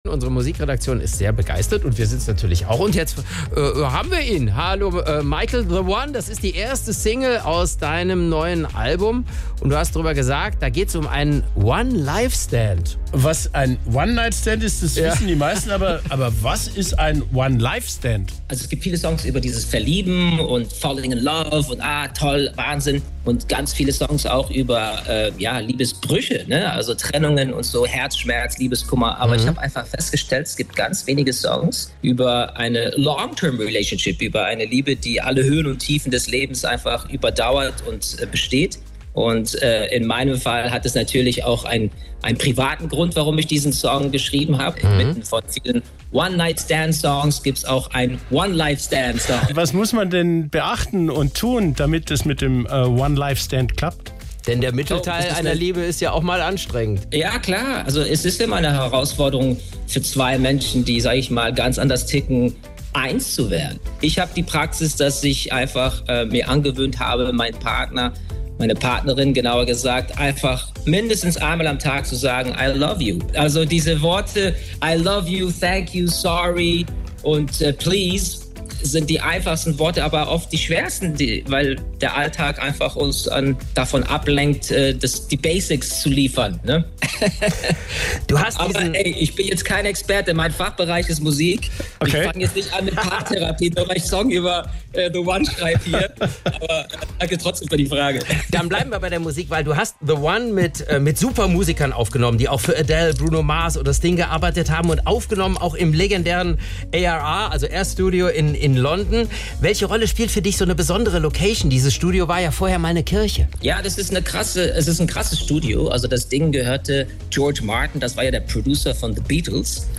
Interview mit Michael Patrick Kelly: „The One“ – und der Unterschied zu anderen Love-Songs